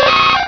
Cri de Nidoran♂ dans Pokémon Rubis et Saphir.